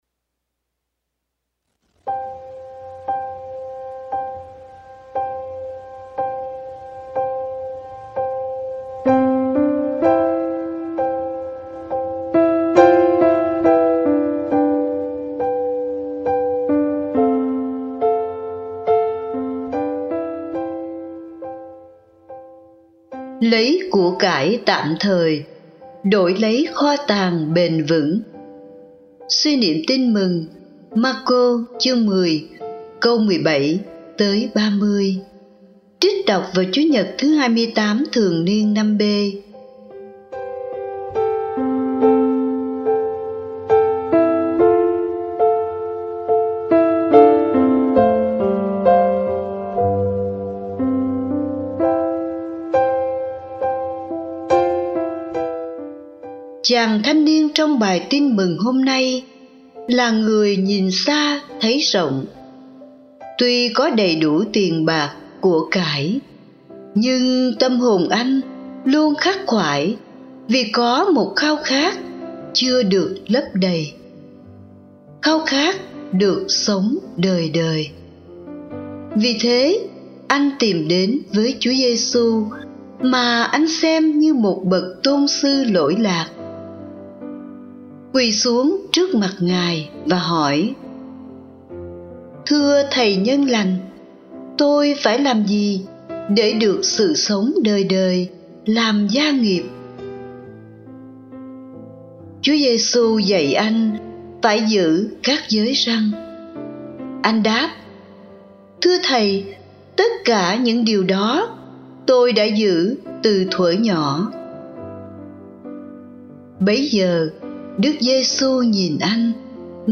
Lấy của cải tạm thời đổi lấy kho tàng bền vững (Suy niệm Tin mừng Chúa nhật 28 TNB)